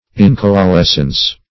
Incoalescence \In`co*a*les"cence\